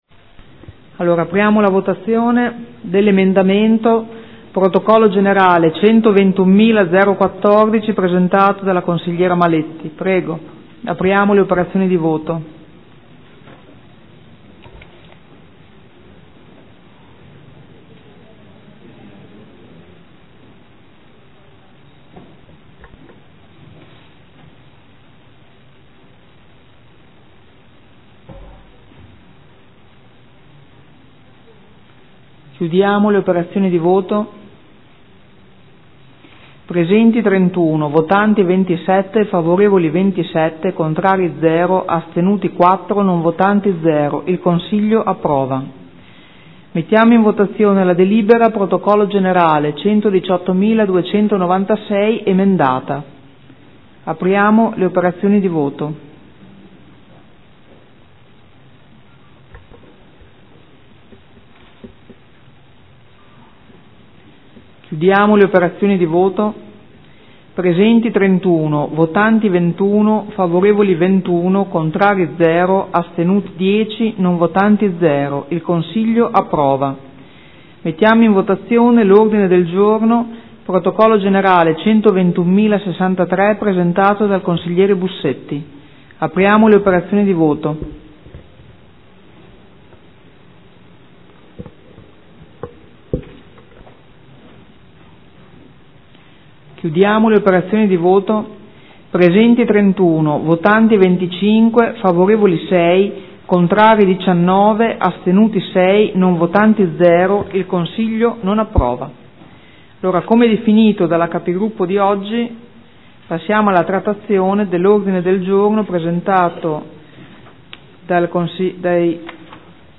Presidente — Sito Audio Consiglio Comunale
Seduta del 10 settembre. Proposta di deliberazione: Definizione degli indirizzi per la nomina e la designazione dei rappresentanti del Comune presso Enti, Aziende, Istituzioni e Società Partecipate: modifica della deliberazione del Consiglio Comunale 4/2015 (Conferenza dei Capigruppo del 10.9.2015).